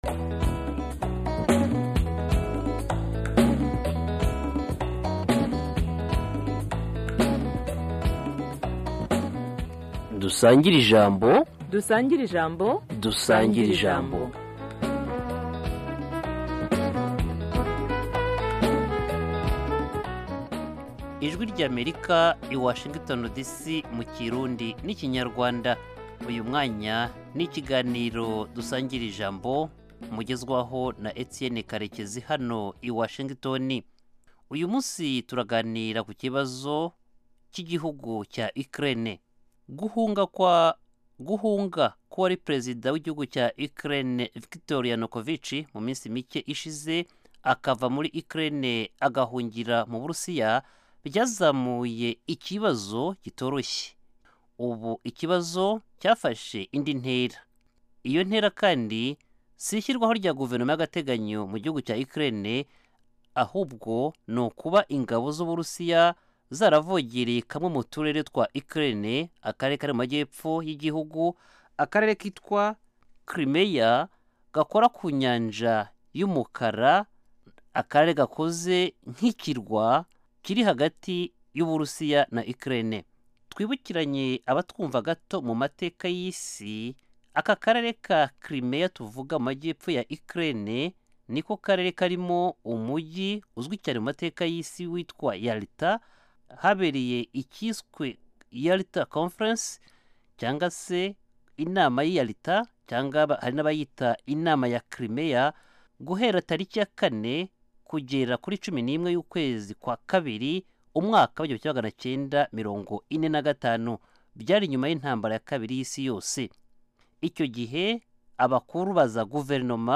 Dusangire-ijambo - Panel discussion and debate on African Great Lakes Region, African, and world issues